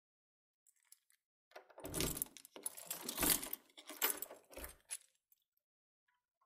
دانلود صدای قفل کردن در با دسته کلید از ساعد نیوز با لینک مستقیم و کیفیت بالا
جلوه های صوتی